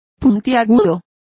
Complete with pronunciation of the translation of spikier.